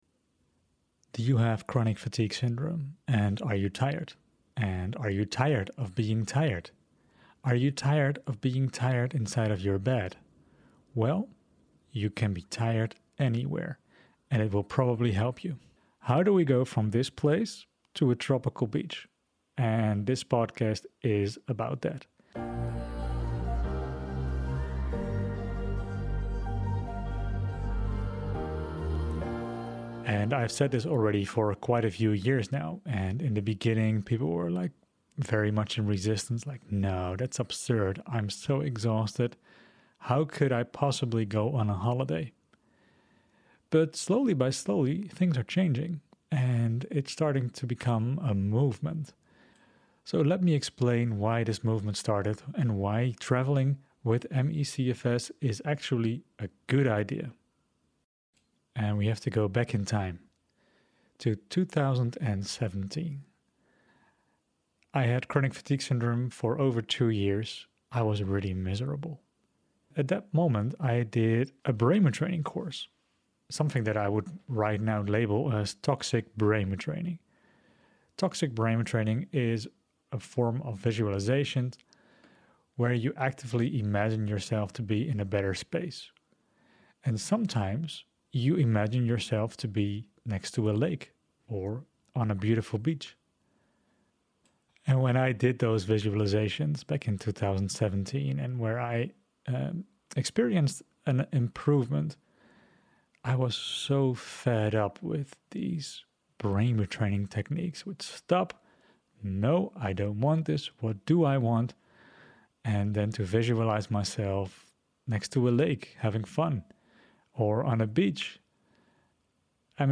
In this heartfelt interview, they share their experiences, struggles, and the profound wisdom they've gained along the way. From early symptoms to life-changing realizations, their story is a testament to resilience and hope.